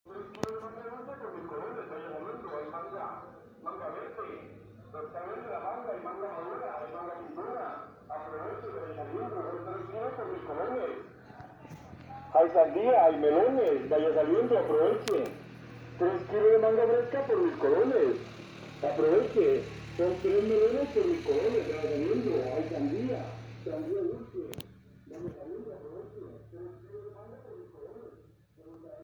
Vendedor de Frutas CARTAGO